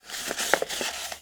bp_box_open1.wav